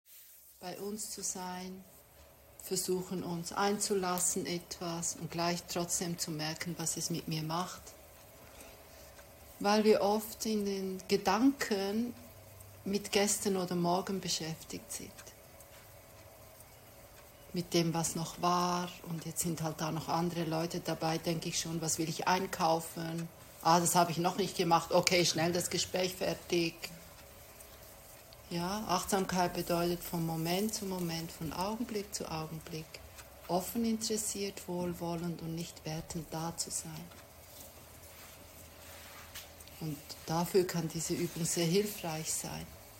Meditation "Was ist Achtsamkeit"